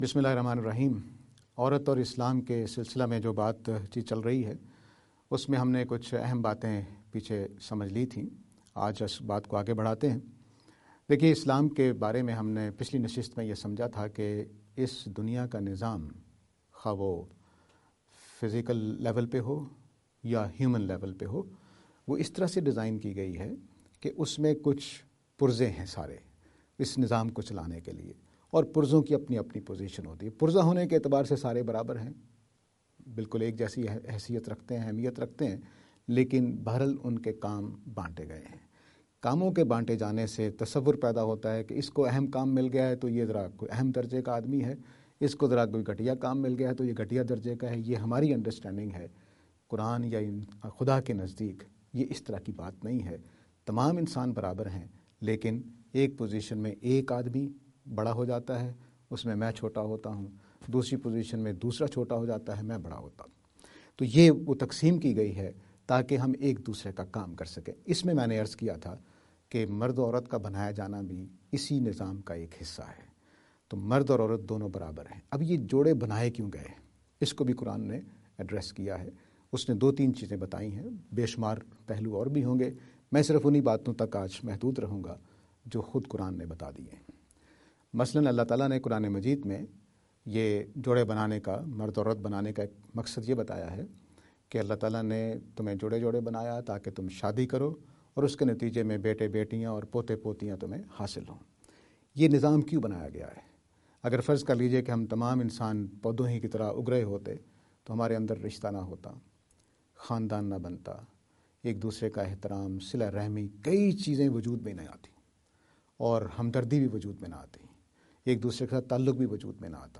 Associate Speakers